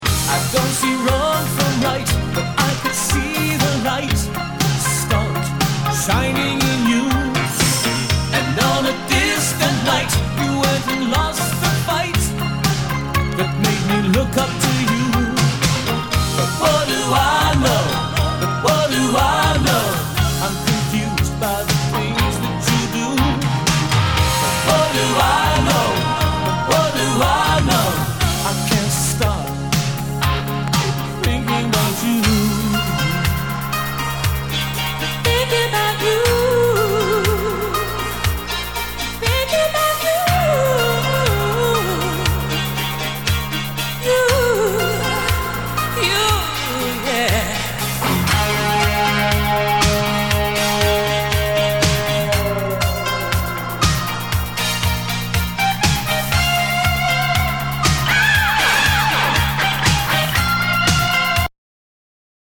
[ Genre ] ROCK/POPS/INDIE